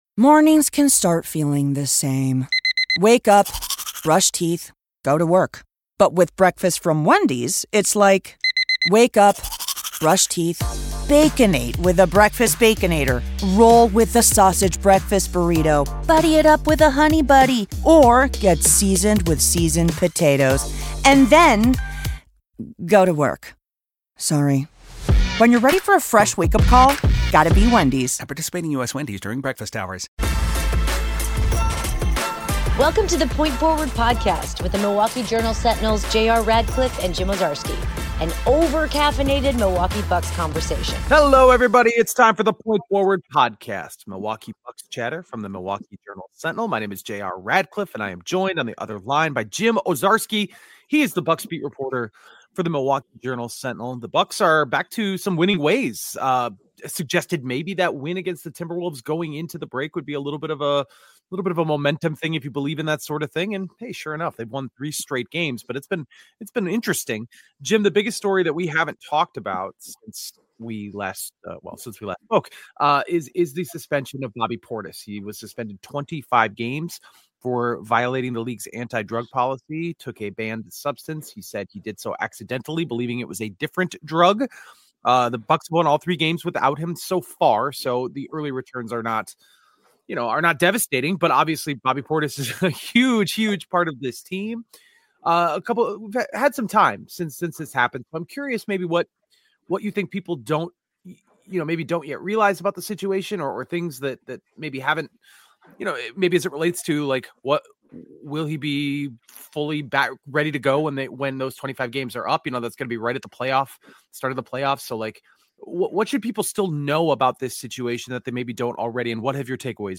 The Bucks get an immediate glimpse of Khris Middleton in a new uniform, and the team is on a bit of a hot run heading into back-to-back showdowns against elite Western Conference teams. Music intro